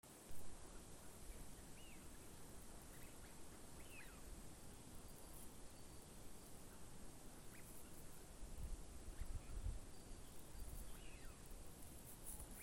Птицы -> Pīles ->
свиязь, Mareca penelope
Skaits100 - 110